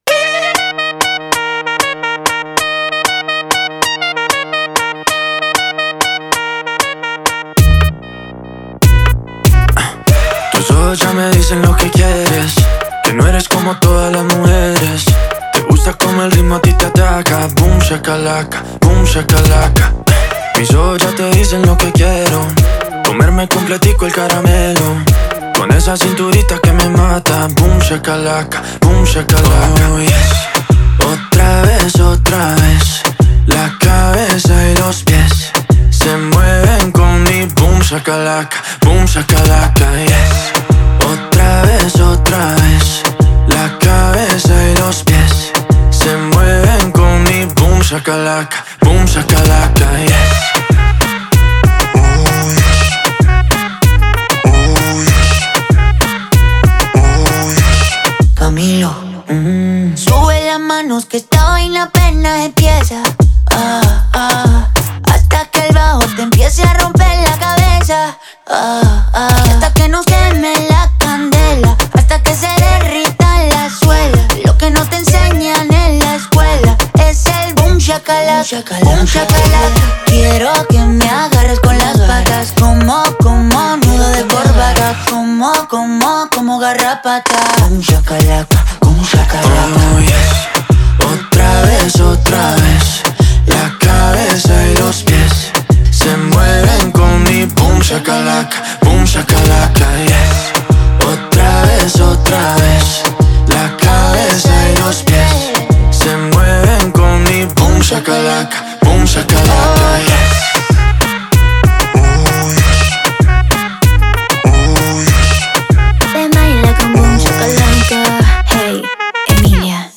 это зажигательный трек в жанре латинской поп-музыки и EDM
Сочетание мелодичных вокалов и танцевальных битов делает